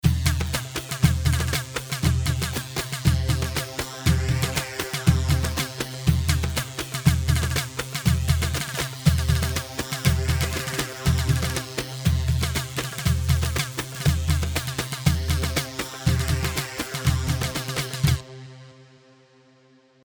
Iraqi
Hellawi 4/4 120 حلاوي